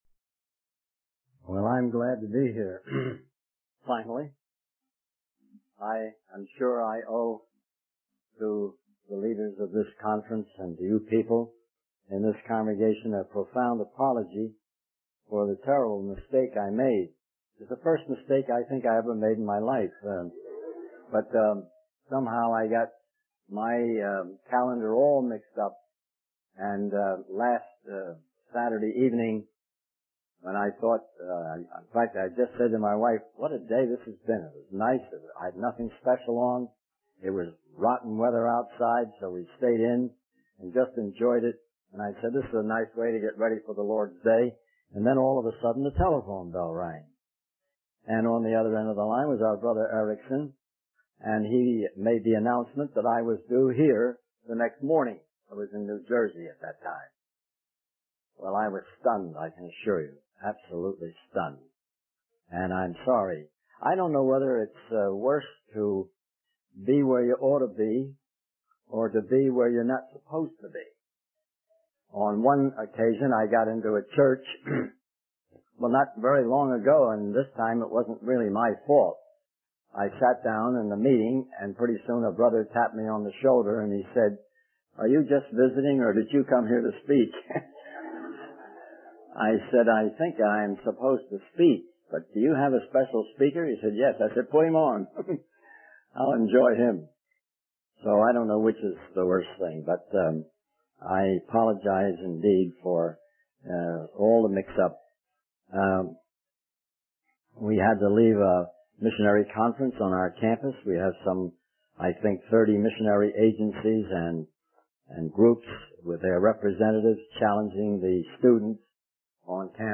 In this sermon, the speaker shares a personal experience of feeling trapped and forgotten, longing for the joy and freedom that others seem to have.